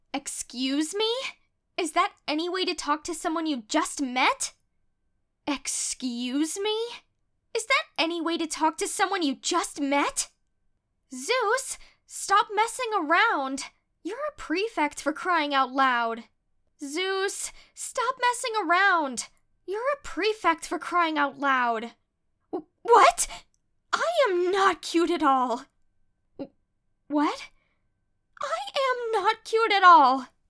The protagonist is currently 19-20, and gets older as the seasons go by.
Voice: High to mid-deep range